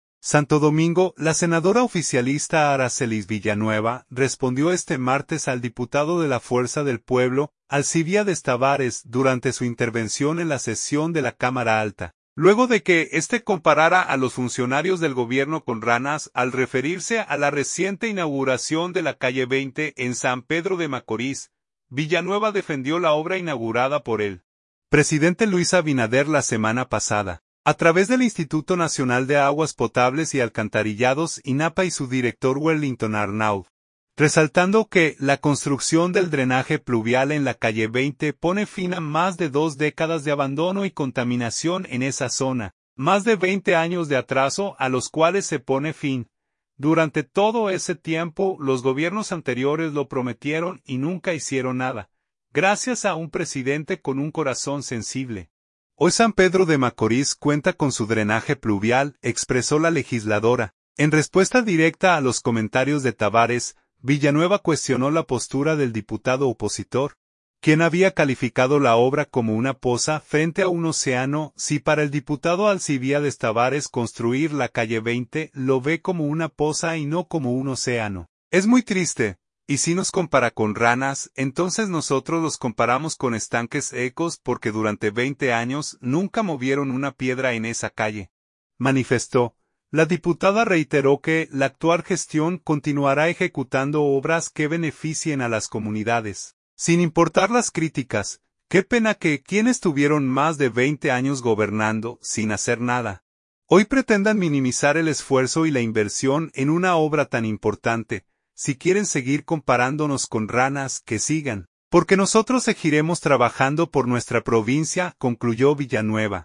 Santo Domingo.– La senadora oficialista Aracelis Villanueva respondió este martes al diputado de la Fuerza del Pueblo, Alcibíades Tavárez, durante su intervención en la sesión de la Cámara Alta, luego de que este comparara a los funcionarios del Gobierno con “ranas” al referirse a la reciente inauguración de la calle 20 en San Pedro de Macorís.